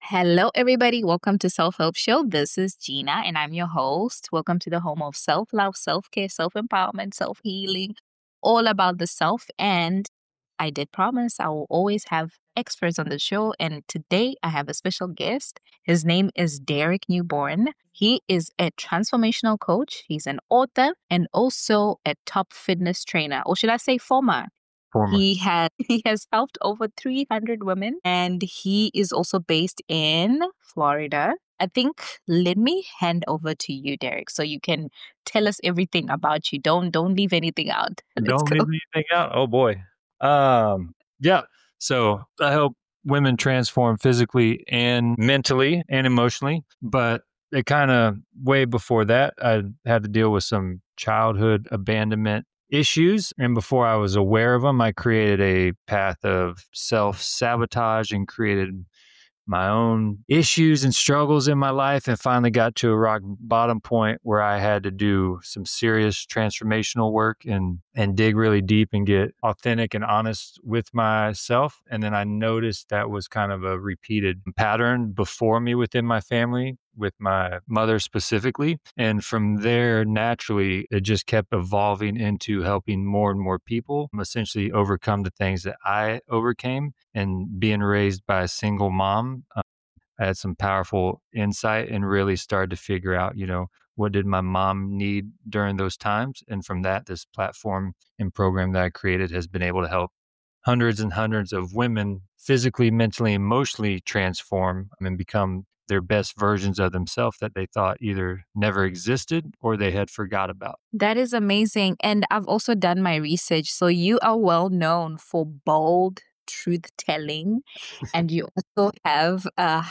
In this first part of our conversation